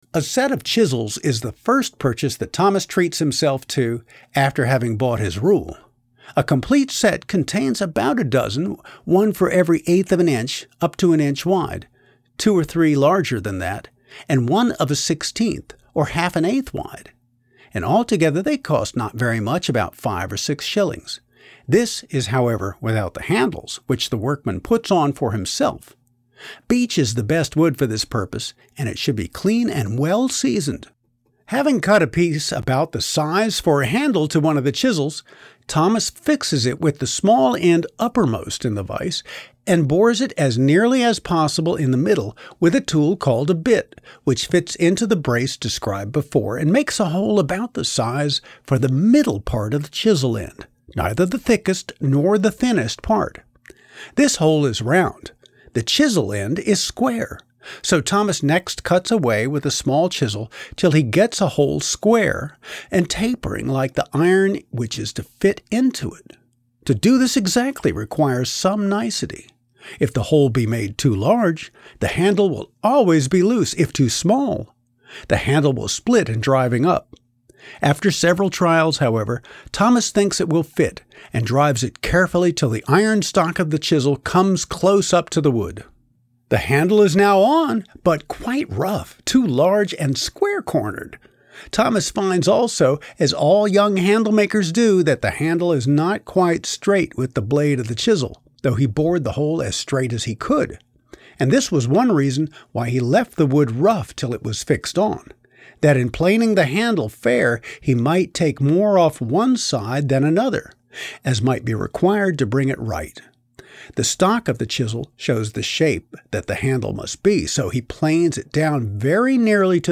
New: ‘The Joiner & Cabinet Maker’ Read By Roy Underhill
The audiobook version of the book consists of the original text only, read by Roy Underhill.
Not only is he a student of early trades, but Roy is also a long-time thespian, and he brought his many voice talents to the project.